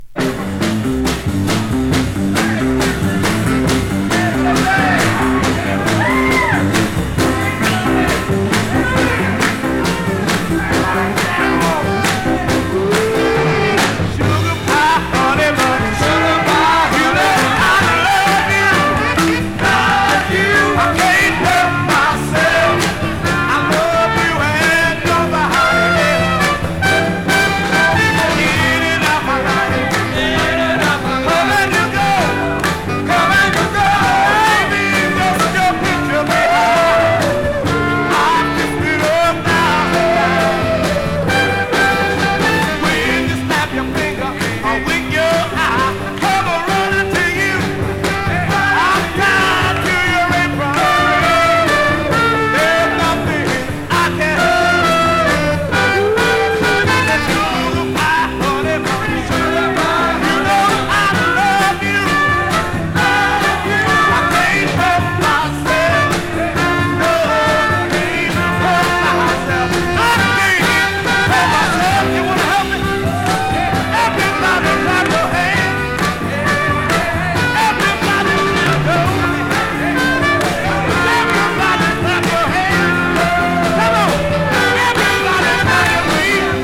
熱狂の坩堝という観客の盛り上がりを封じ込めたライヴ・アルバムです！＊音の薄い部分でチリチリ・ノイズ。時折パチ・ノイズ。